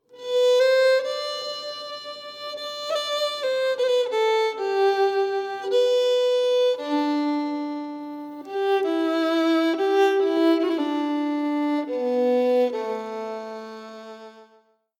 Realization including bowings and ornaments.